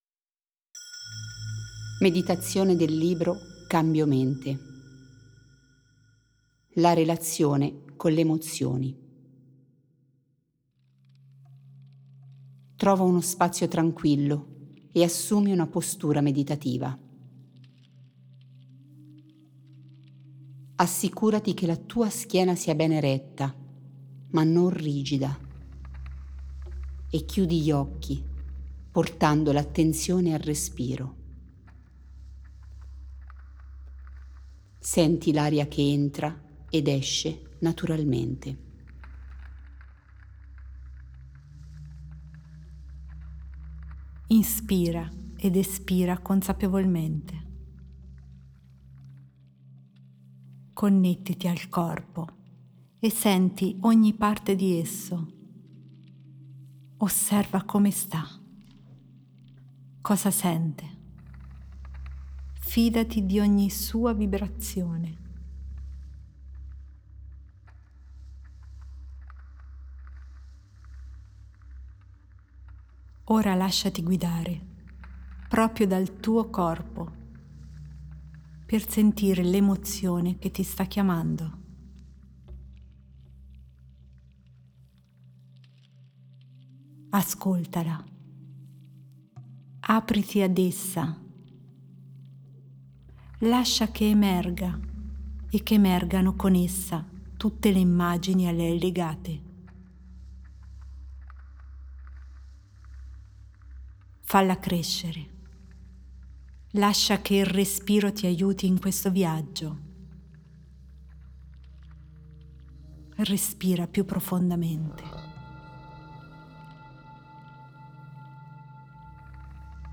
Meditazione - La relazione con le emozioni